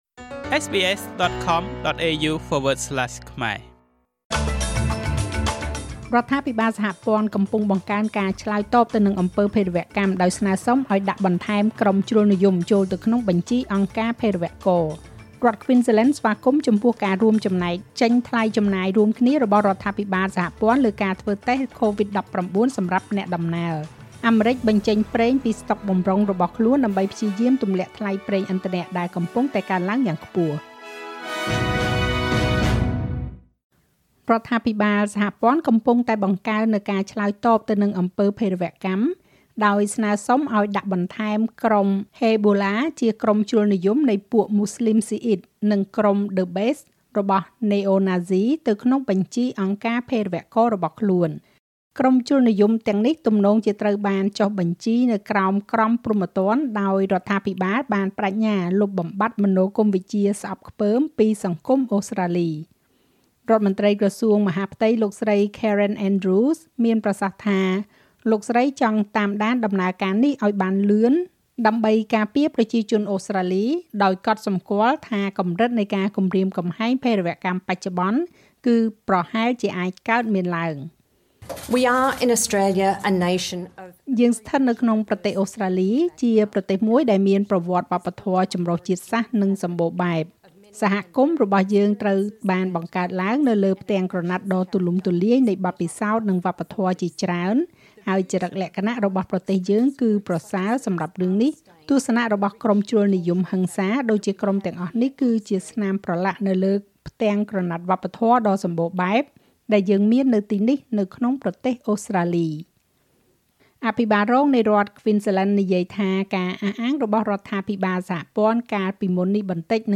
ស្តាប់មាតិកាព័ត៌មានចុងក្រោយបង្អស់ក្នុងប្រទេសអូស្រ្តាលីពីវិទ្យុSBSខ្មែរ។